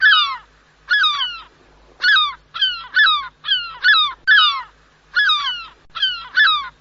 gaviotaas
gaviotaas.mp3